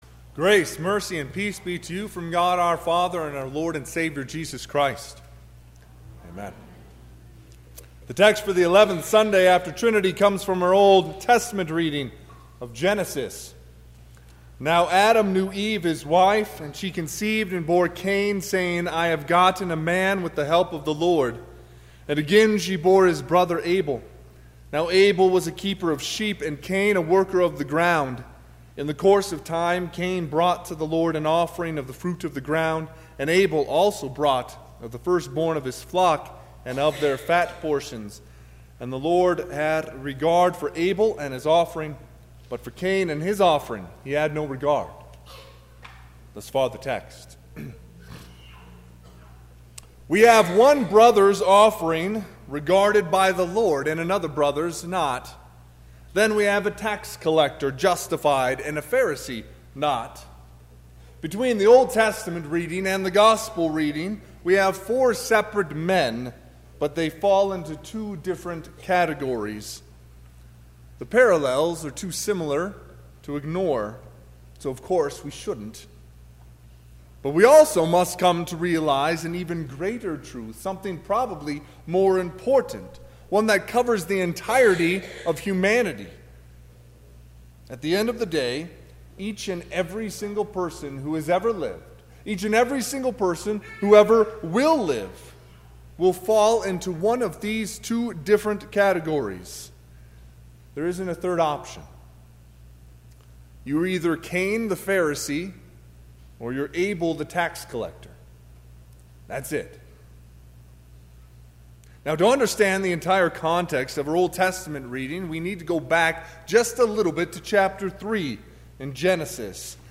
Sermon – 9/1/2019
Sermon_Sept1_2019.mp3